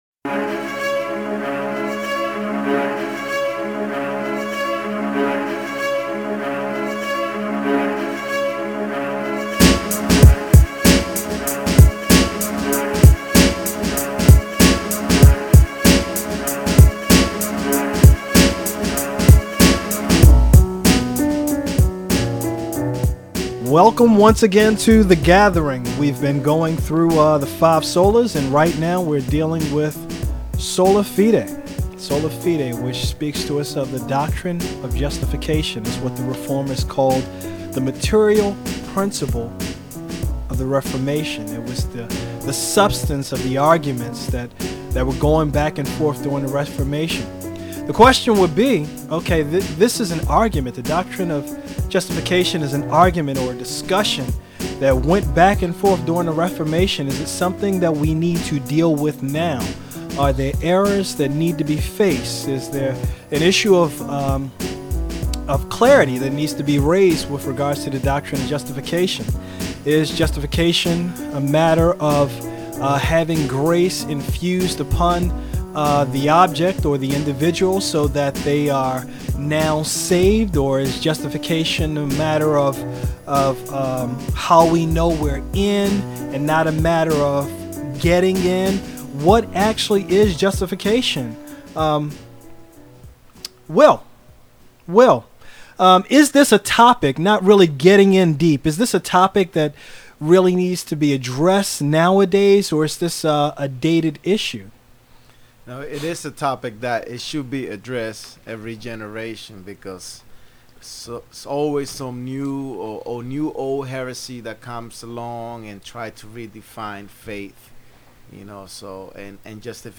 The brothers of The Gathering sit and discuss the doctrine of Faith alone - sola fide. How is a man made right before God ?